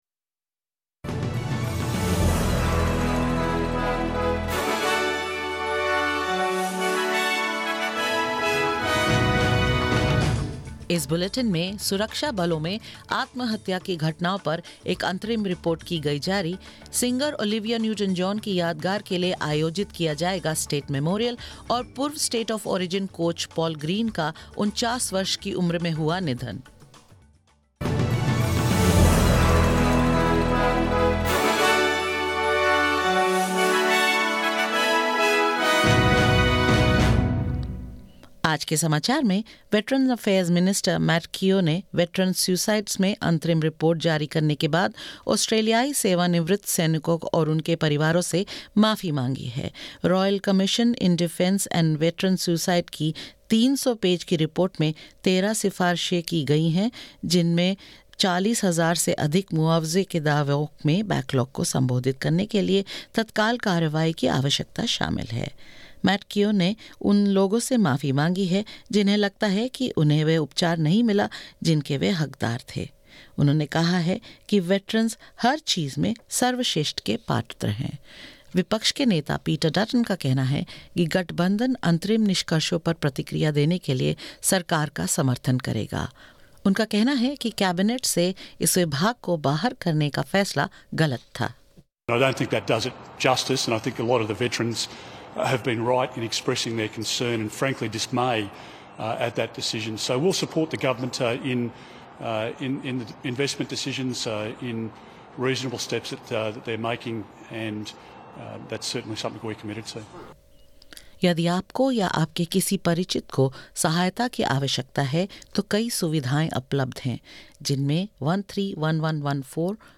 In this latest SBS Hindi bulletin: The Royal Commission releases its interim report into Defence Force suicides today: Premier Daniel Andrews says planning underway for a state memorial for Olivia Newton-John; In sports, the family of former NRL player Paul Green grieves his sudden death and more.